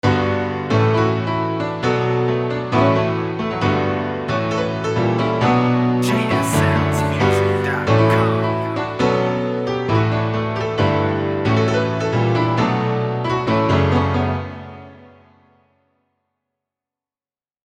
Details 100% Original Upbeat Piano Loop Tempo and key included Midi included Tag removed Preview
JS-Sounds-Piano-Vibe-5-B-minor-134-bpm_mp3.mp3